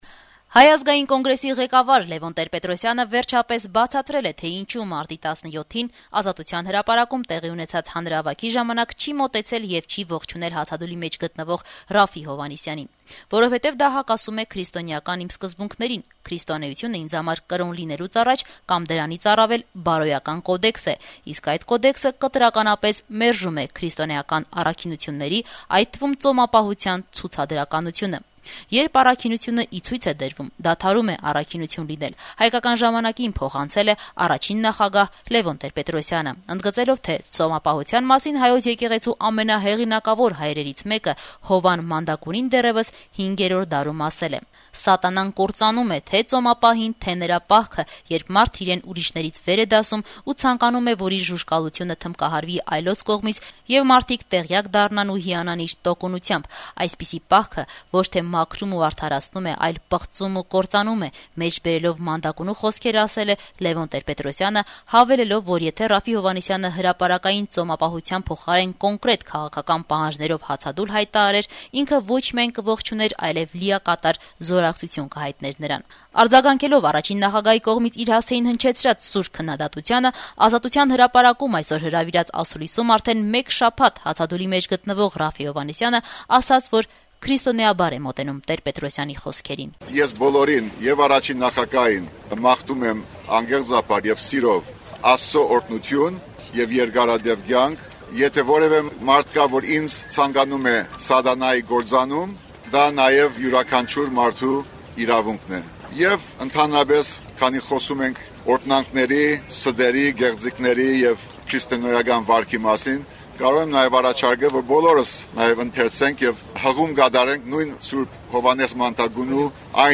Այդ մասին «Ժառանգություն» կուսակցության առաջնորդը հայտարարեց երեքշաբթի օրը Ազատության հրապարակում հրավիրած ասուլիսի ժամանակ: